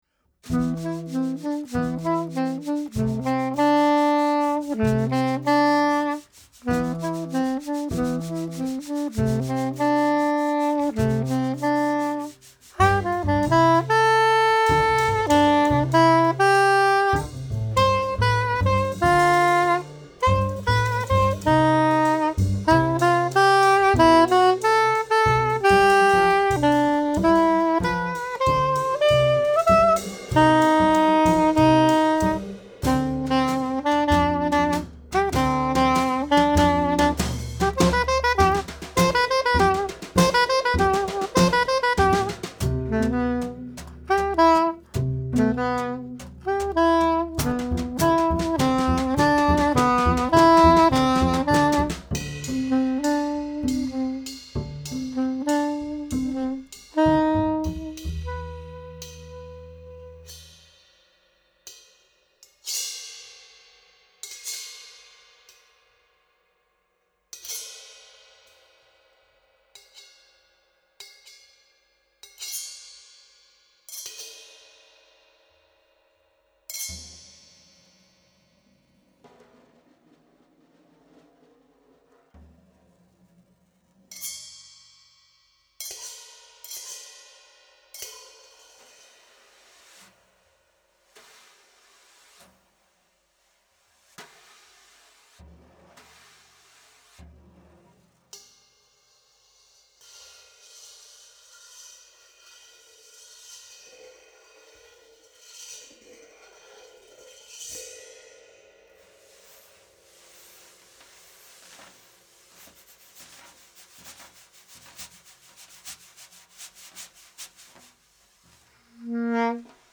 alto, soprano, C-merlody
double bass, voice
drums